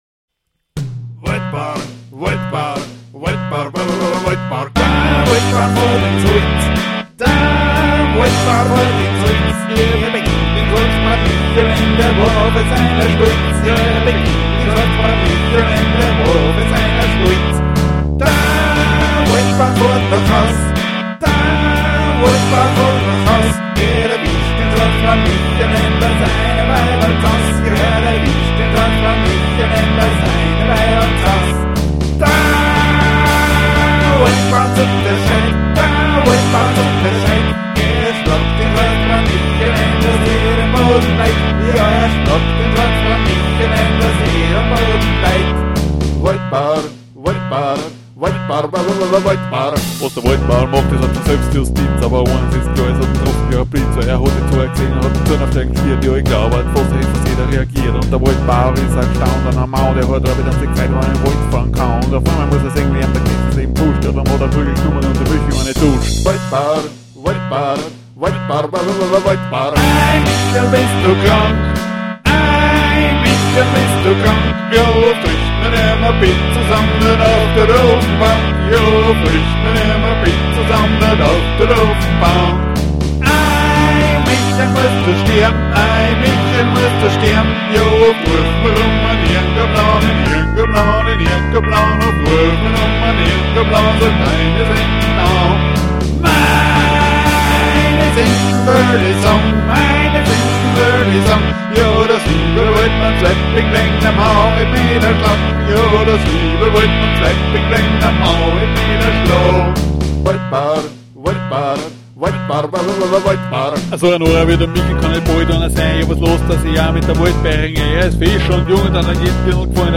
My version of a traditional song.